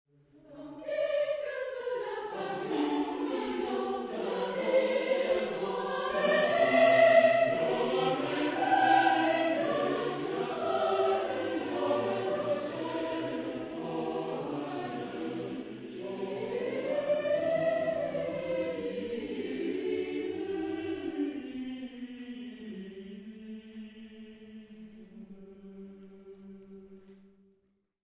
Chansons et madrigaux, chœur à trois ou quatre voix mixtes et piano ad libitum